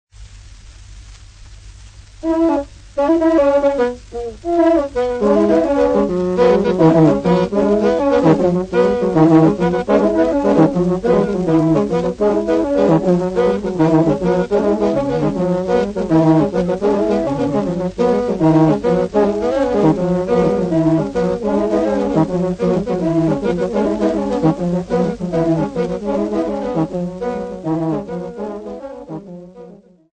Abanyabyala Royal Band
Folk Music
Field recordings
Africa Uganda city not specified f-ug
Indigenous music